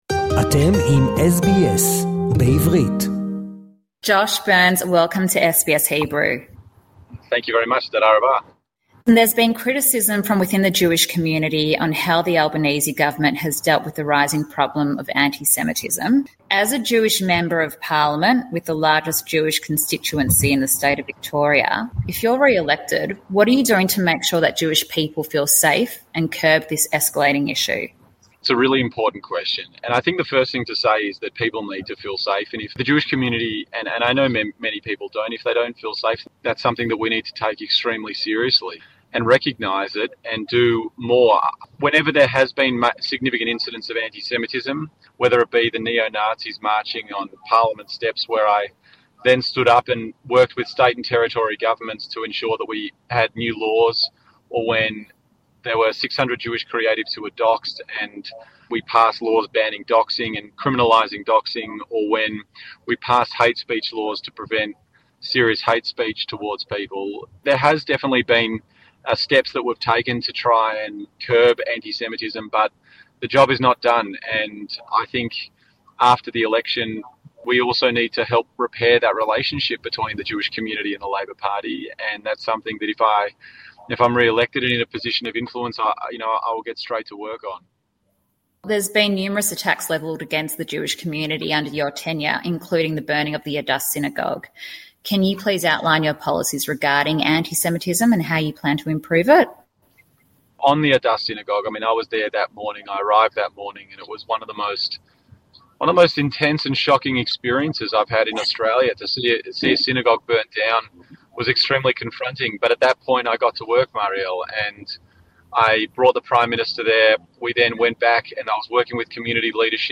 Conversation with MP Josh Burns, Labor candidate for the Victorian seat of Macnamara, about his record and his vision for a safe, inclusive future for Jewish Australians.